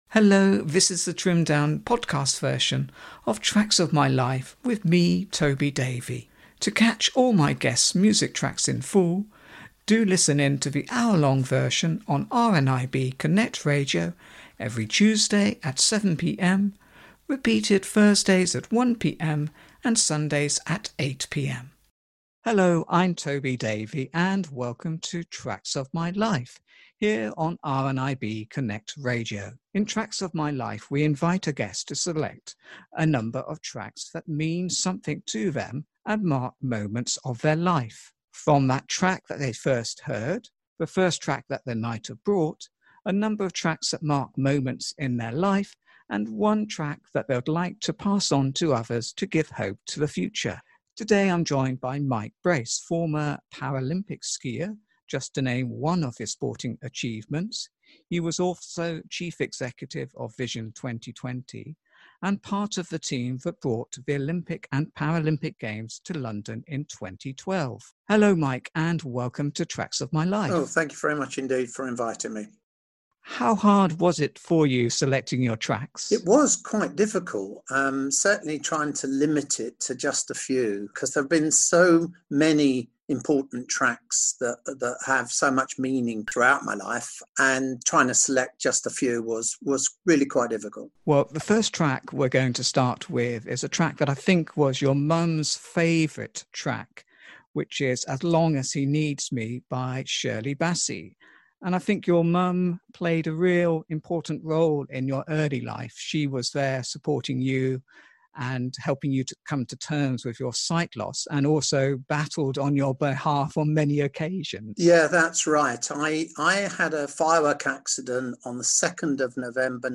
In this trimmed down podcast version of Tracks of My Life